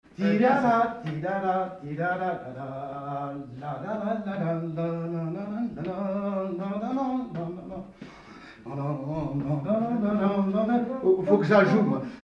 Polka à quatre pas de Samoëns chantée
danse : polka
circonstance : bal, dancerie
Pièce musicale inédite